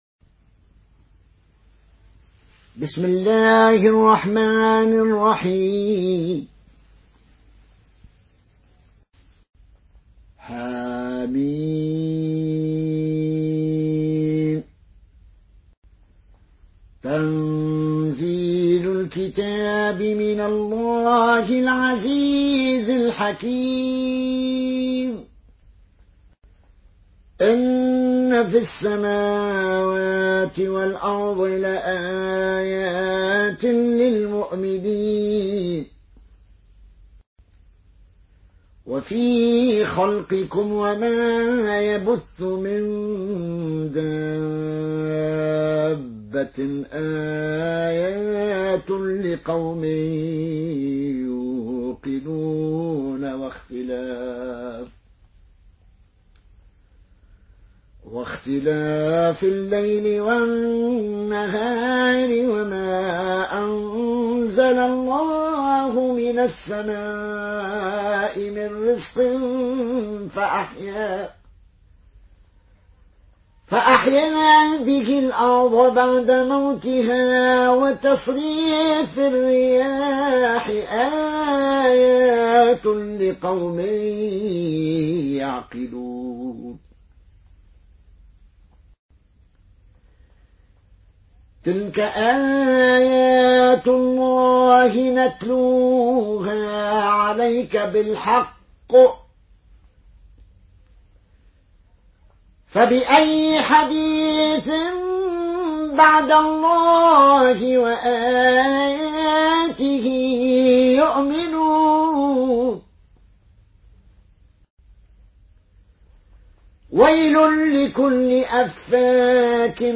Murattal Qaloon An Nafi